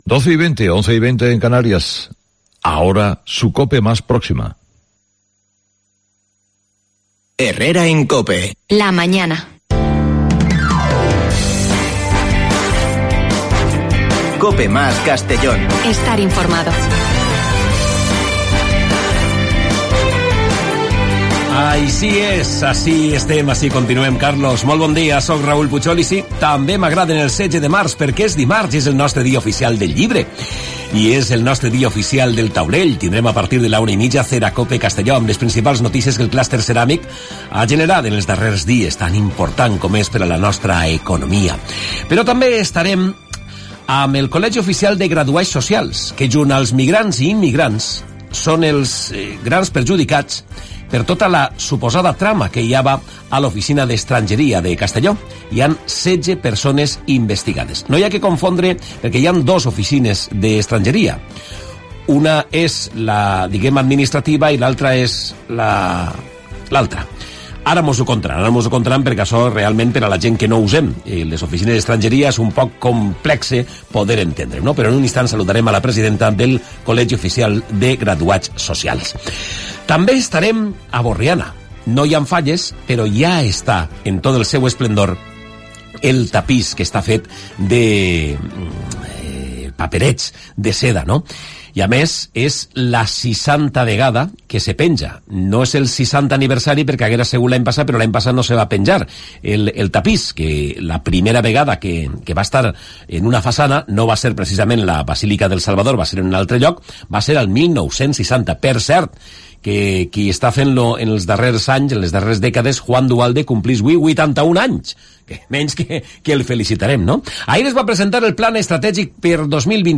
Magazine provincial